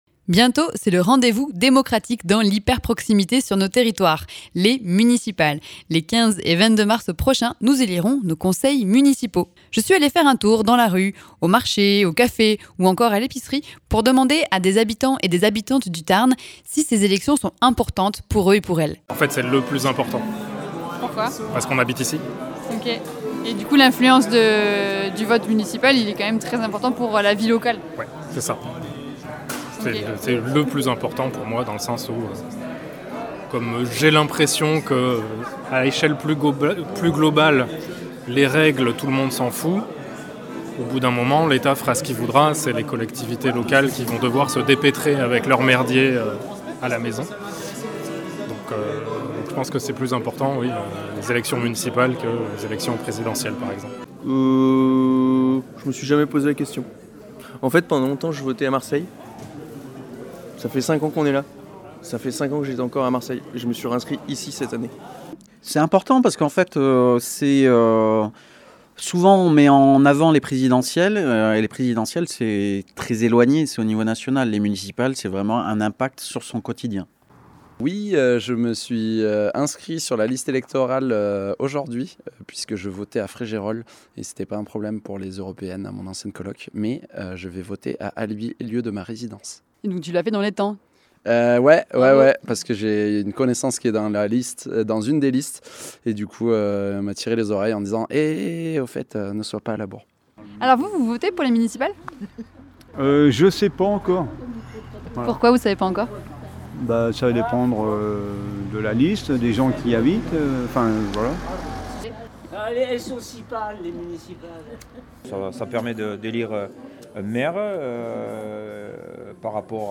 À l’approche des élections municipales des 15 et 22 mars, nous sommes allés tendre le micro dans le Tarn pour recueillir les paroles de celles et ceux qui feront le scrutin : les habitants. Attentes pour leur commune, envie — ou non — d’aller voter, priorités du quotidien… un micro-trottoir au plus près des préoccupations des Tarnais et Tarnaises.